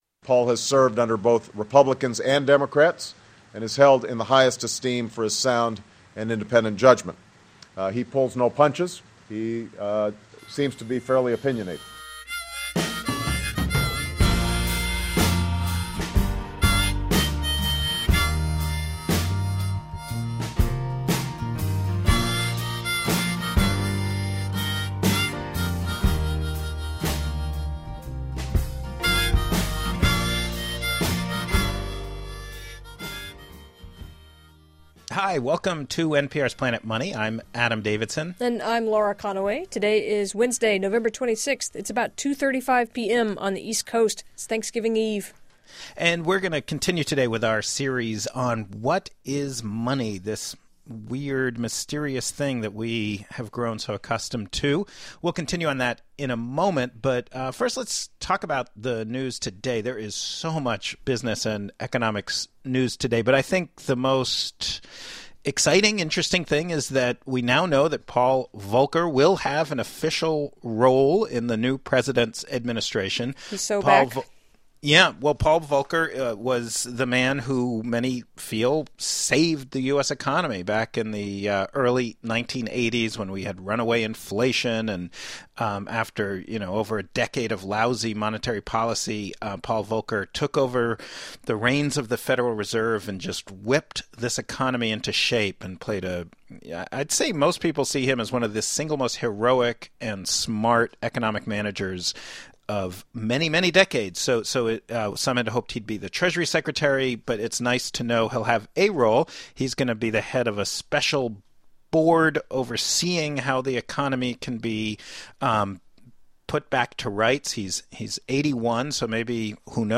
Adam Davidson takes former Treasury Under Secretary Peter Fisher out for street food, plus a mindblowing curbside chat on which kind of money is what.